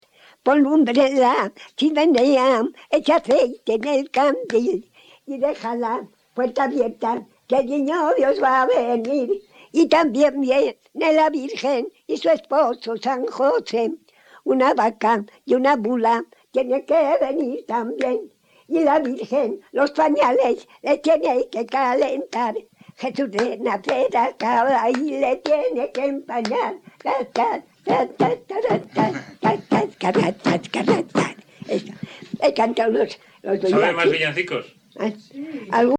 Grabación realizada en Valladolid, en 1977.
Género / forma: Canciones populares-Valladolid (Provincia) Icono con lupa
Canciones populares Icono con lupa